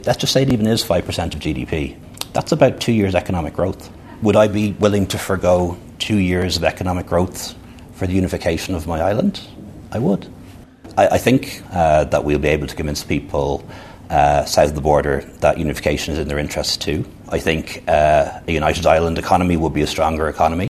The former Taoiseach made the comments during an interview with BBC Northern Irelands Red Lines podcast.